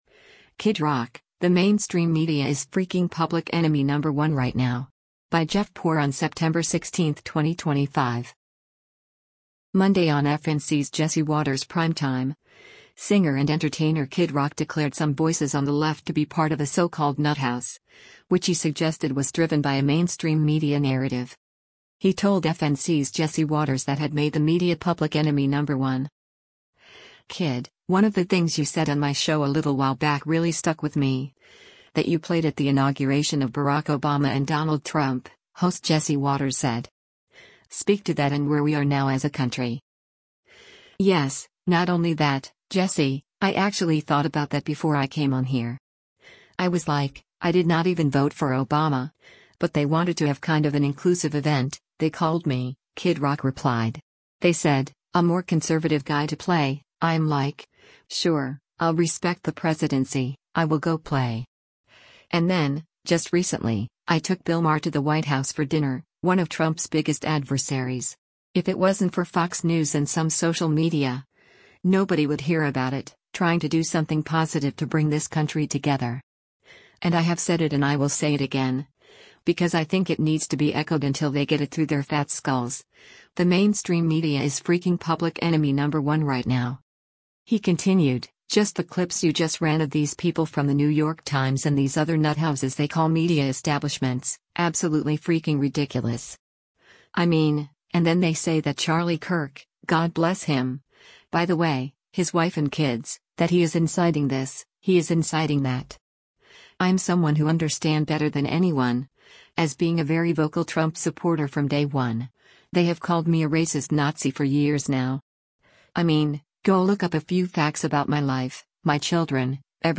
Monday on FNC’s “Jesse Watters Primetime,” singer and entertainer Kid Rock declared some voices on the left to be part of a so-called “nuthouse,” which he suggested was driven by a mainstream media narrative.